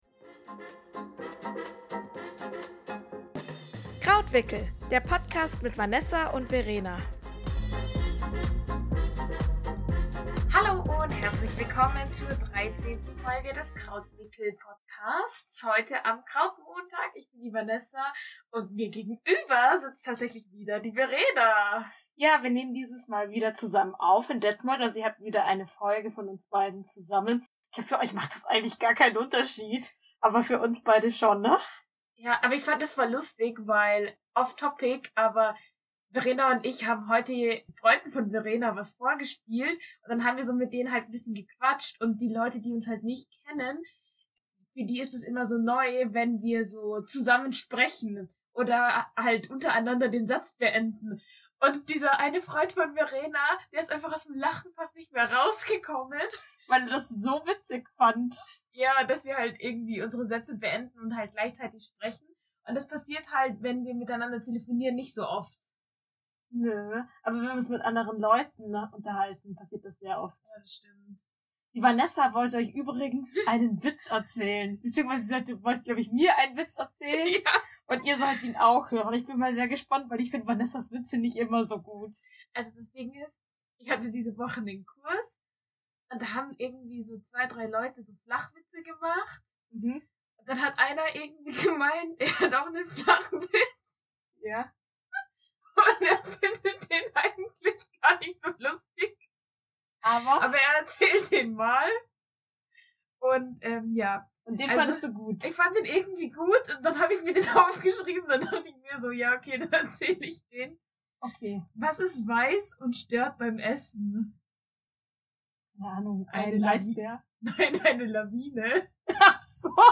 Besser spät als nie, obwohl diese Folge klingt als hätten wir sie in einem U-Boot aufgenommen.